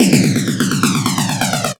F X     49.wav